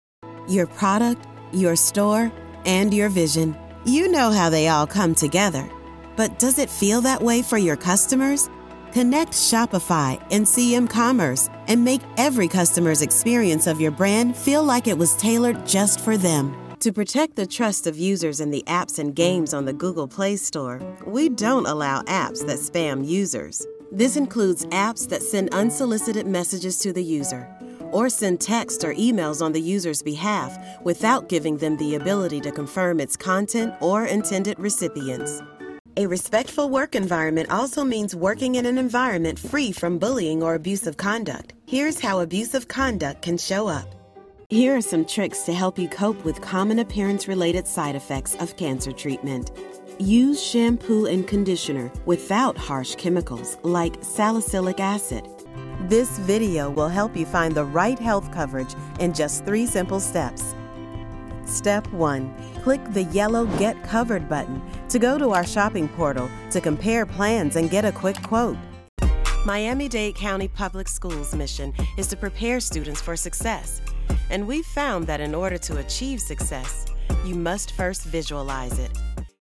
Has Own Studio
explainer video